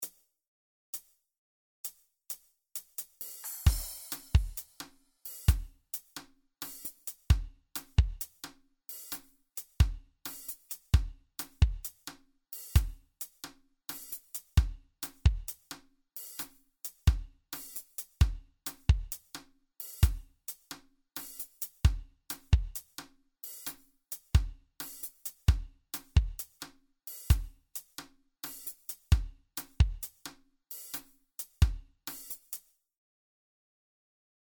il portale sul mondo delle congas e delle percussioni - web portal for congas and percussion world
Description Listen to this groove at t=132bpm.